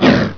attack.wav